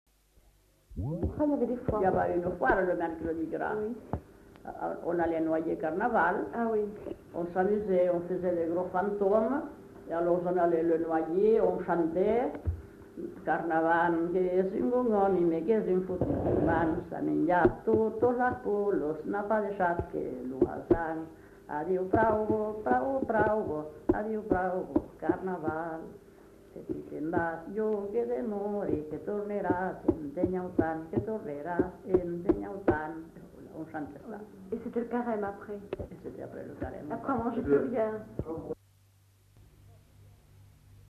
[Brocas. Groupe folklorique] (interprète)
Genre : chant
Type de voix : voix de femme
Production du son : chanté ; récité
Description de l'item : fragment ; 1 c. ; refr.
Classification : chanson de carnaval